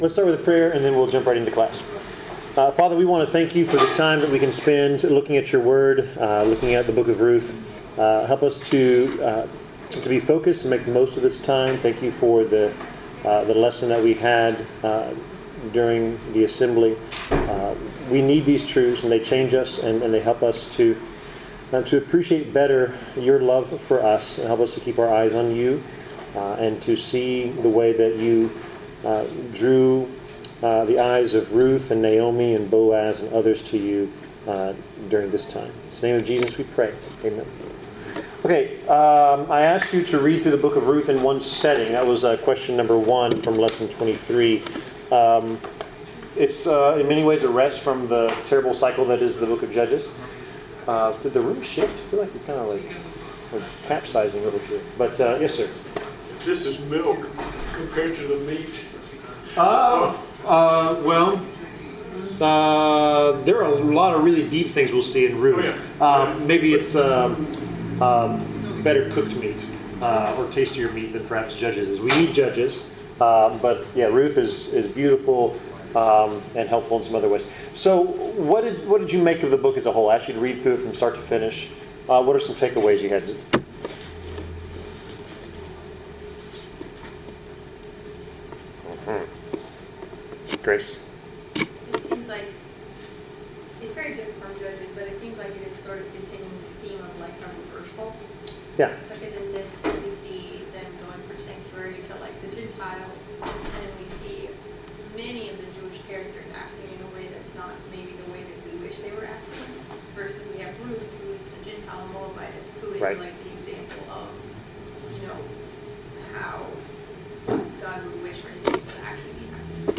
Service Type: Bible Class